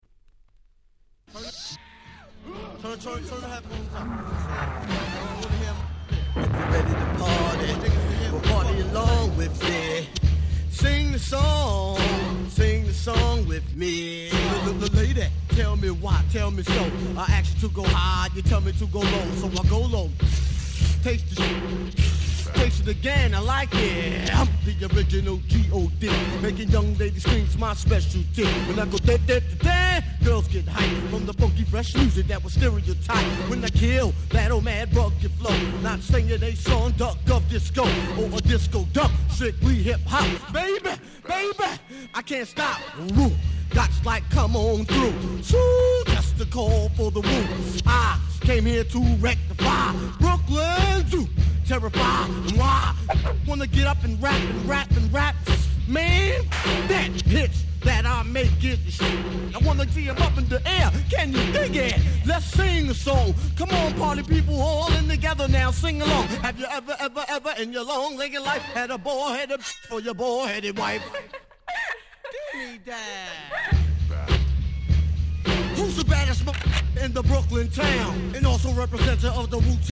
これぞ90's Hip Hop !!